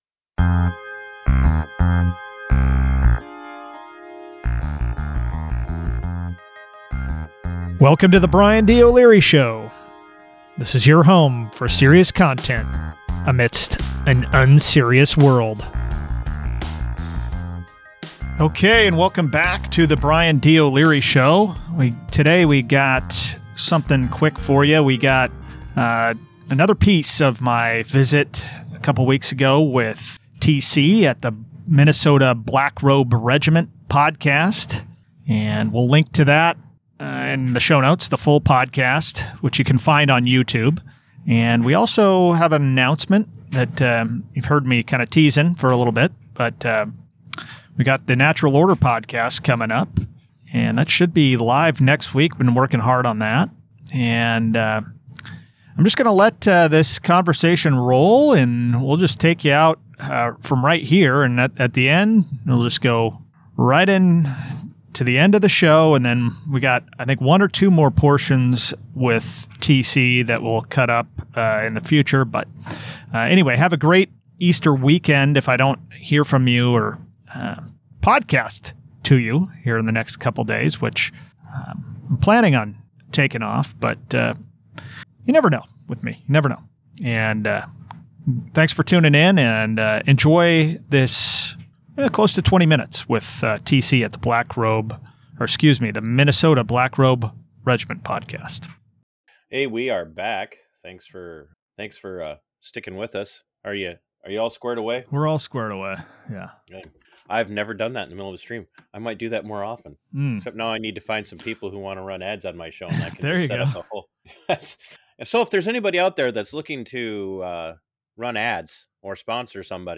We’ll drop the rest of the interview in pieces over the next few weeks.